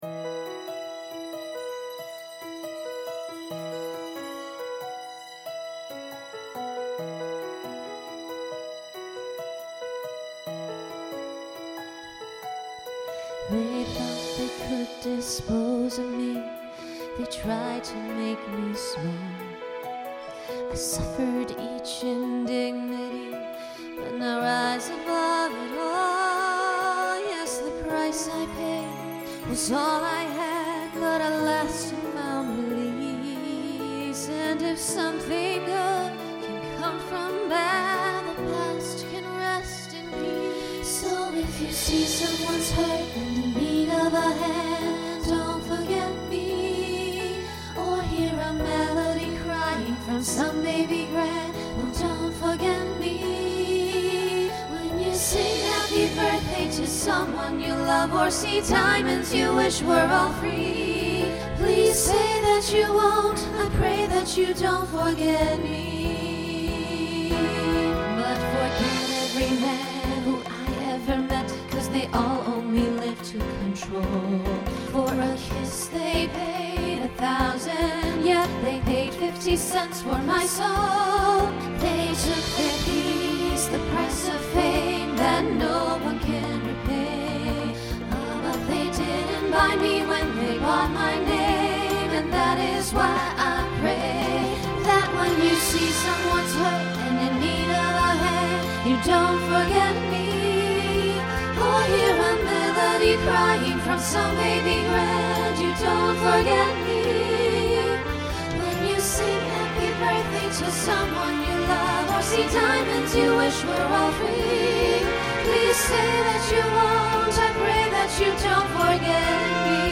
Voicing SSA Instrumental combo Genre Broadway/Film